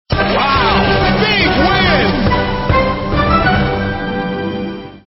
jackpot_bigwin.mp3